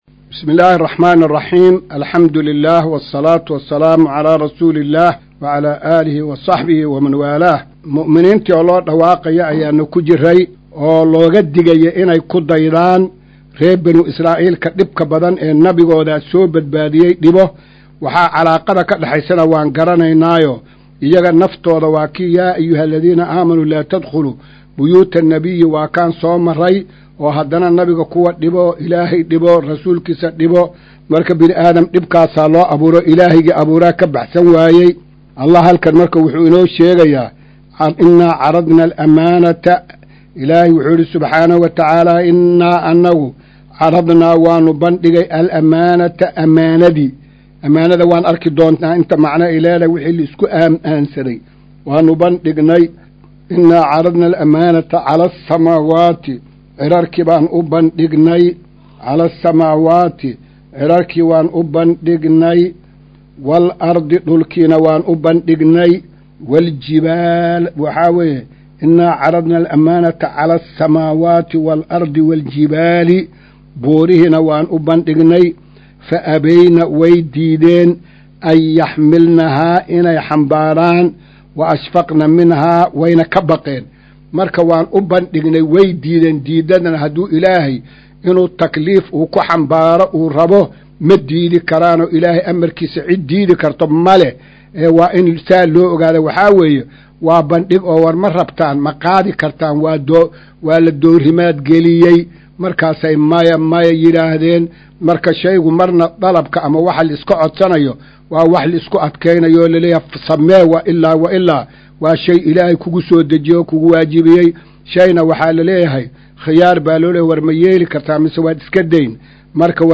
Maqal:- Casharka Tafsiirka Qur’aanka Idaacadda Himilo “Darsiga 202aad”